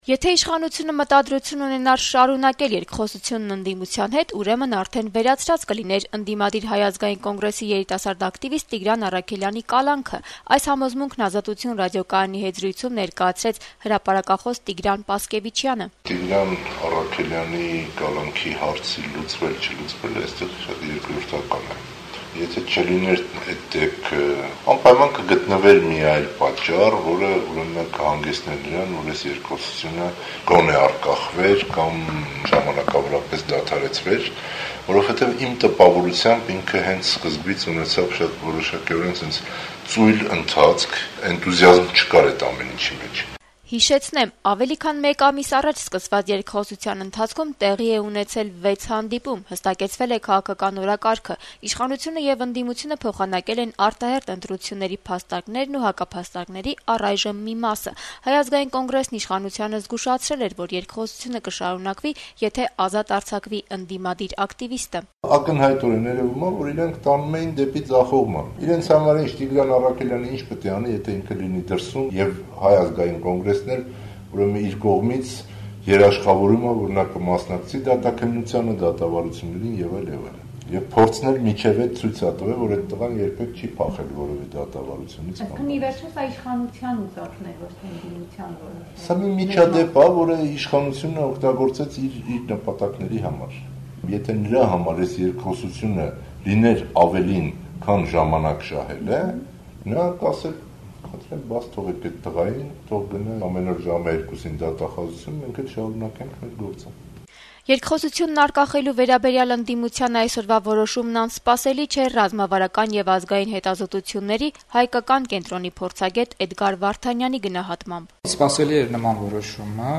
հրապարակախոս
քաղաքական վերլուծաբան